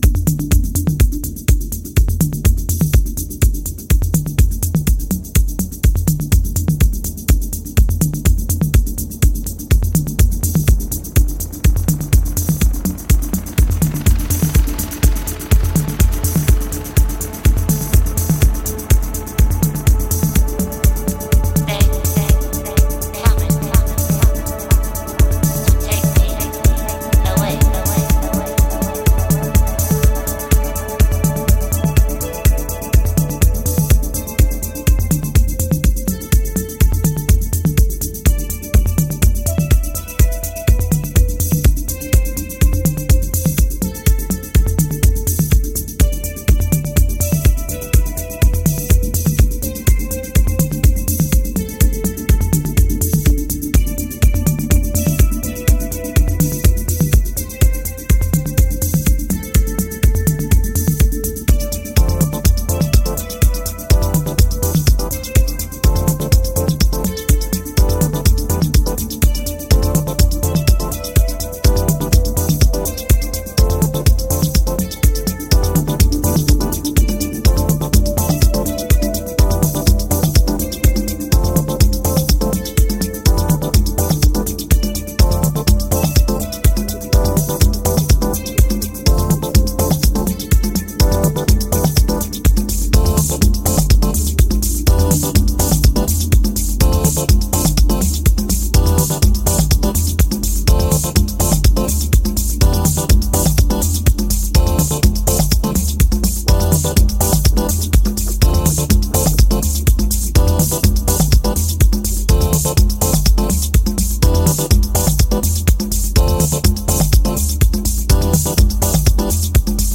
French deep house